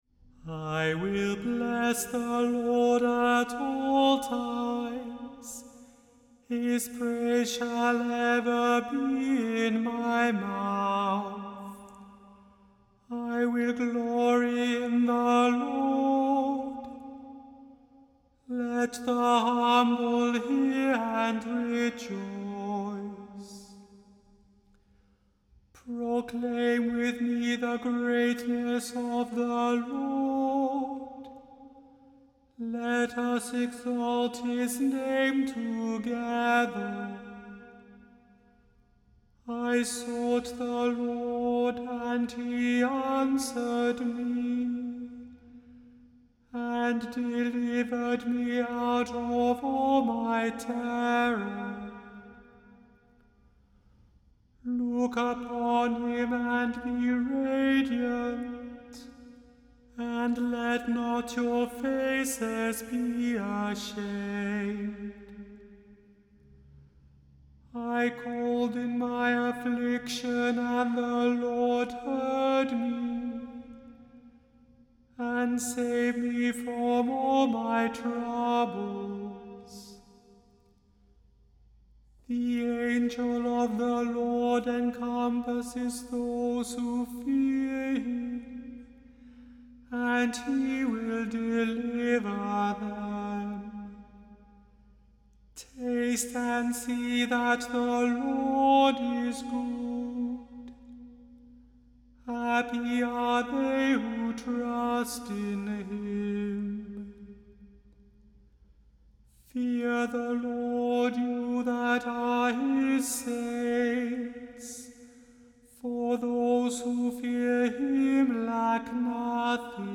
The Chant Project – Chant for Today (March 27) – Psalm 34